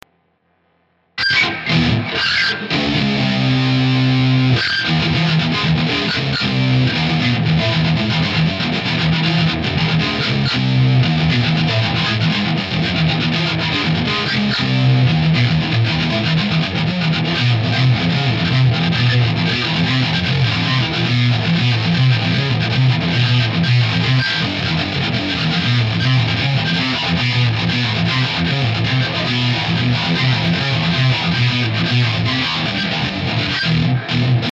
Вниз  Играем на гитаре
Записал на телефон и прогнал через Риг